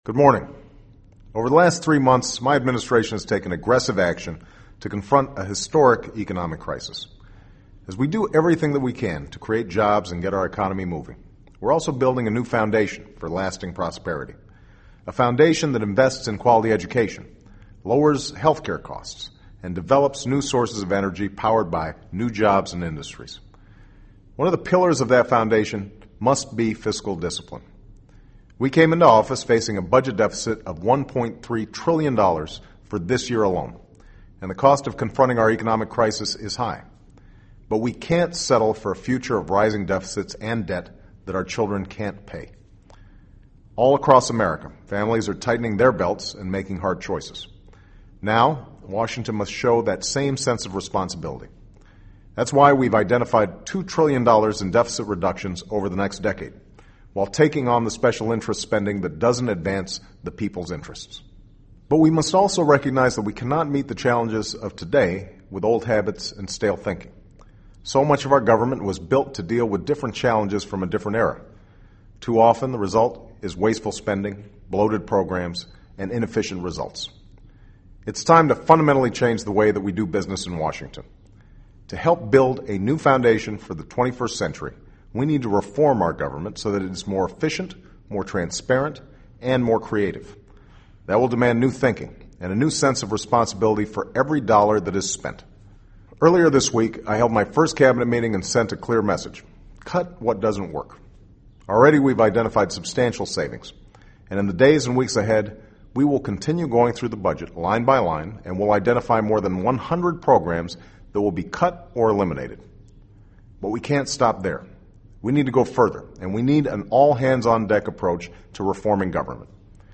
【美国总统电台演说】2009-04-25 听力文件下载—在线英语听力室